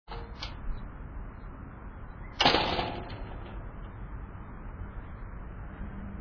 door2.mp3